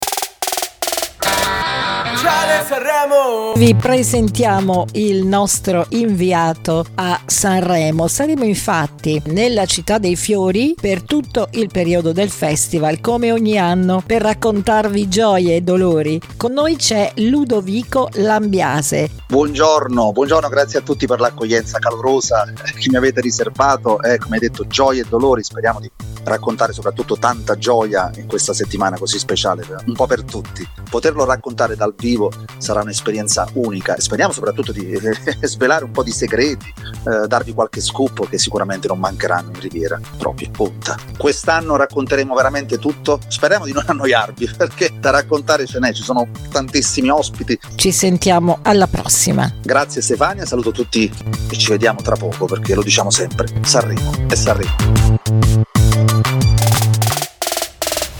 Il format prevede aggiornamenti e interviste agli artisti partecipanti ed è suddiviso in due appuntamenti giornalieri da Martedì 11 a Sabato 15 Febbraio e un appuntamento Domenica 16 Febbraio 2025.